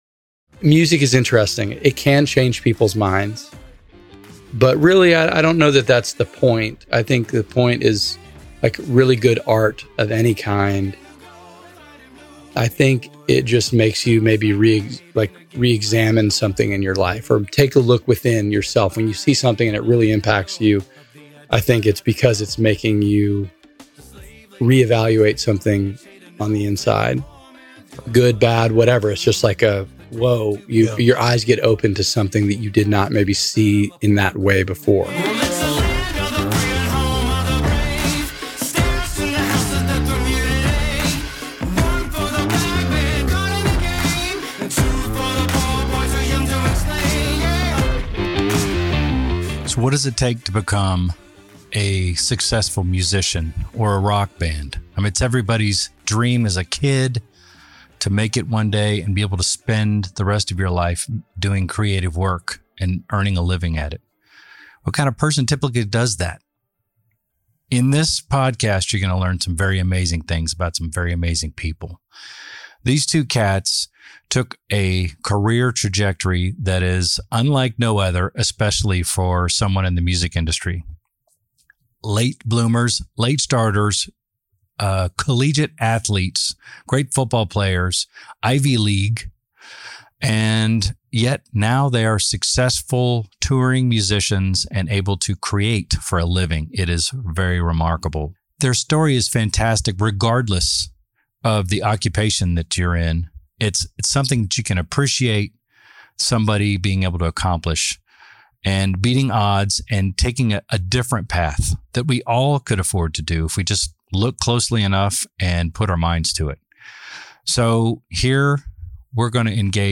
Is there a best practice for becoming a successful musician, artist, businessperson, entrepreneur, or athlete?In this fascinating interview, we follow two unforeseen paths and recognize the value of mindset and determination.